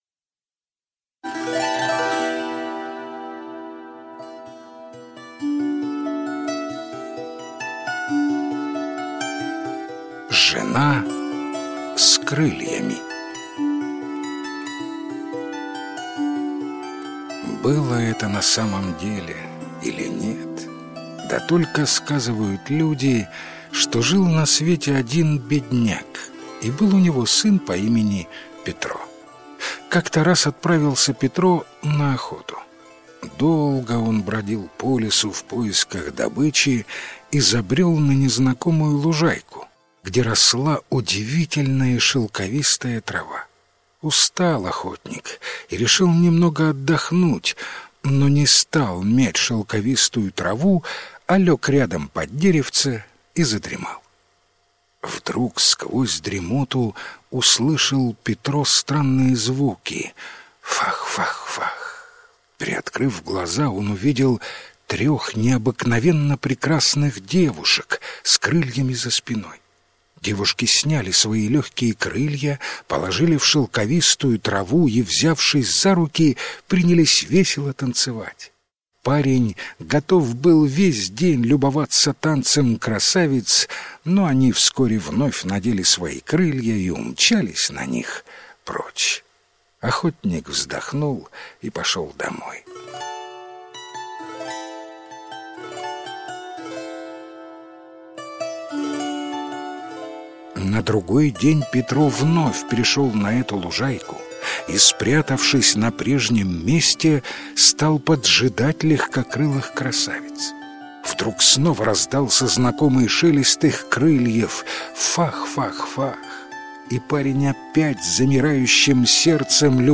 Жена с крыльями - украинская аудиосказка - слушать онлайн